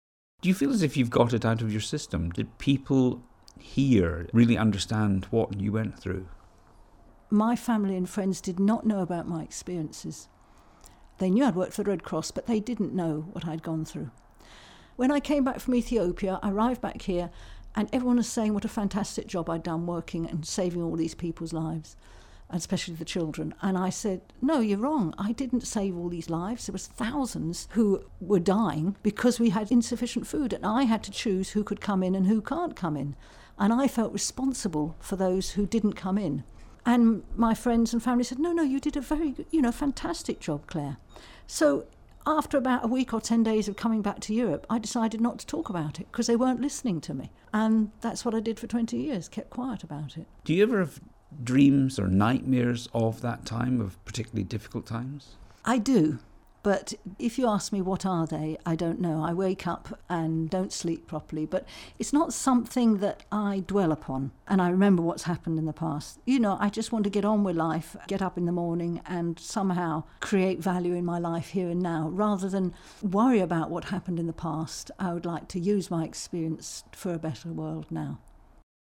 Anglo-Swiss nurse Claire Bertschinger recalls how she had to choose who would eat during the famine in Ethiopia.